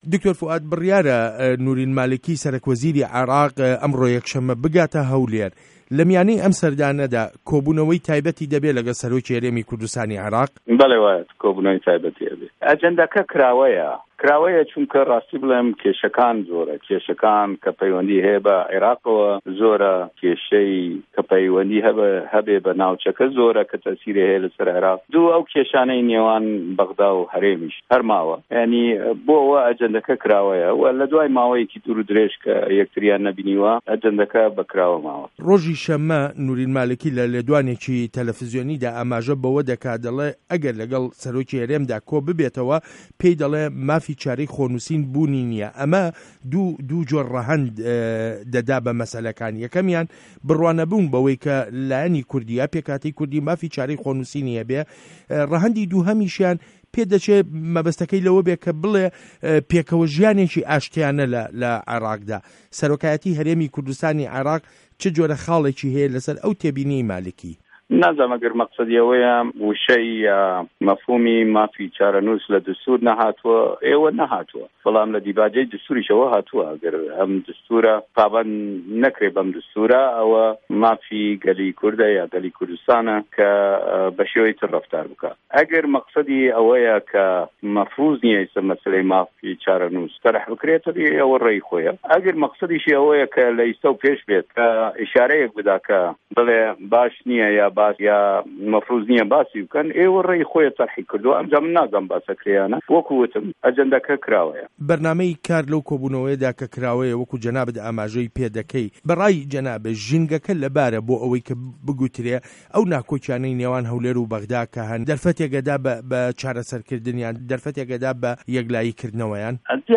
وتووێژ له‌گه‌ڵ دکتۆر فوئاد حسێن